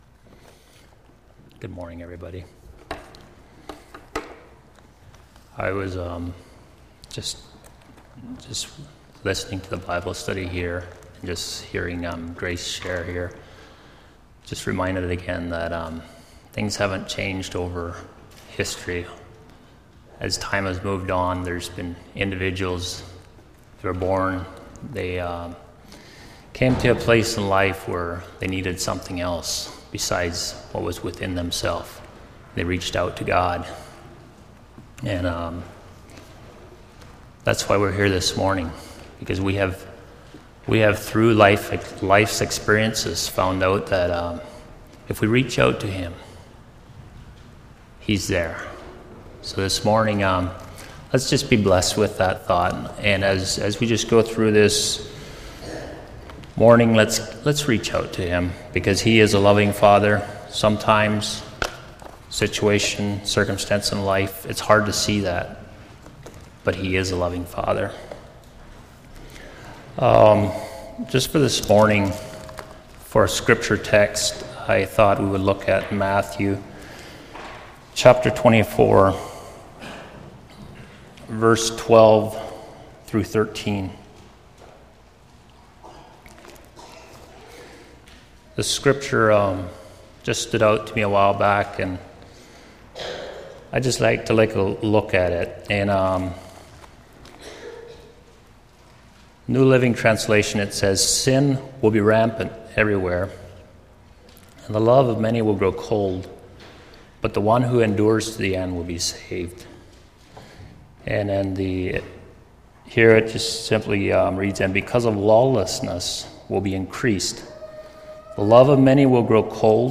Series: Sunday Morning Sermon